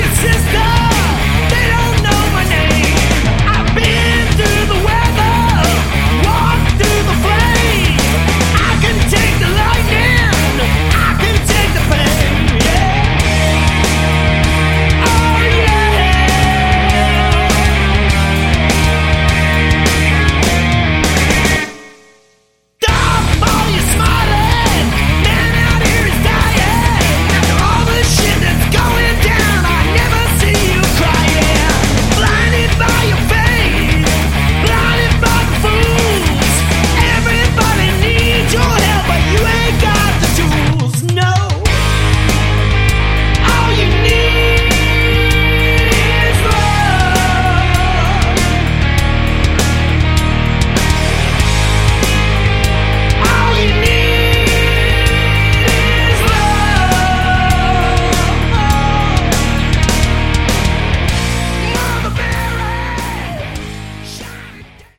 Category: Sleaze Glam
vocals
guitar
bass
drums